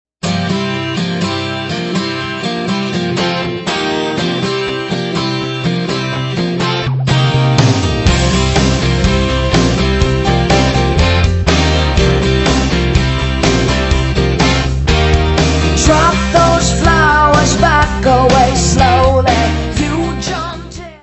Music Category/Genre:  Pop / Rock